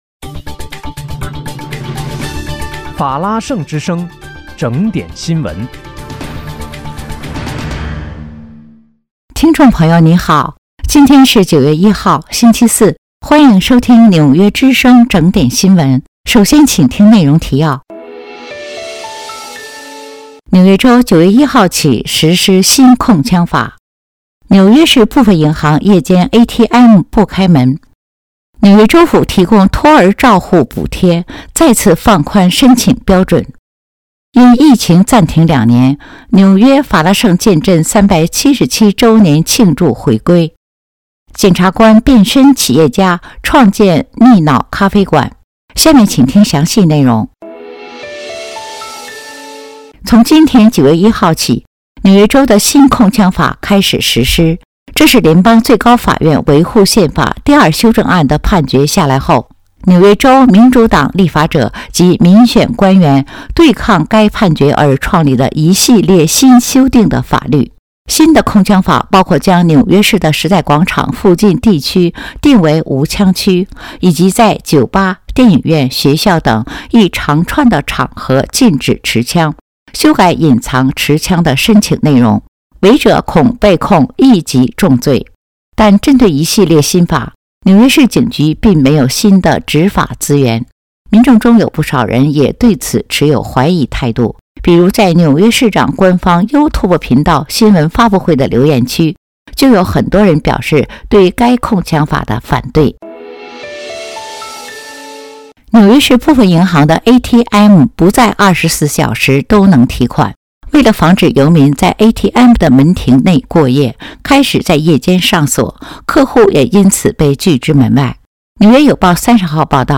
9月1日（星期四）纽约整点新闻